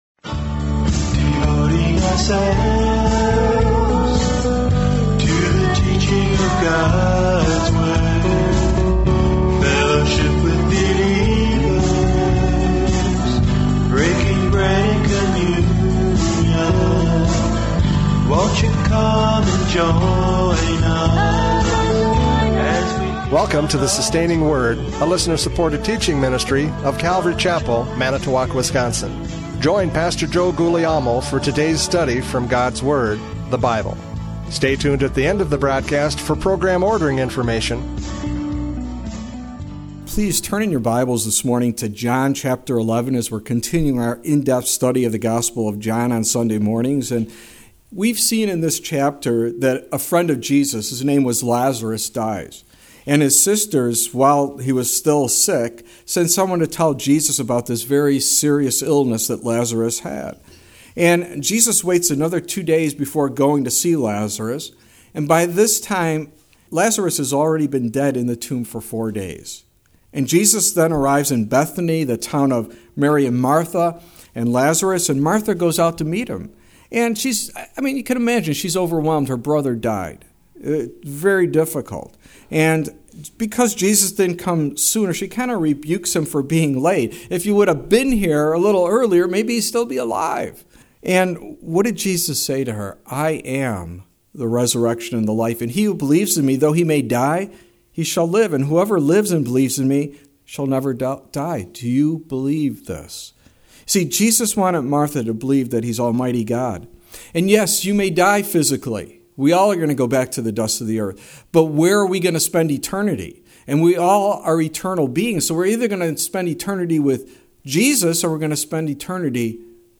John 11:45-57 Service Type: Radio Programs « John 11:28-44 “But Lord